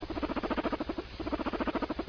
purr,
purr.ra